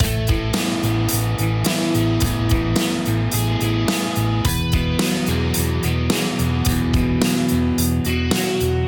まず、Push 100%：スネアが前のめりになっている音源です。
この楽曲で言えば、前に進む感じをより高められるPushの方が合っていると思います。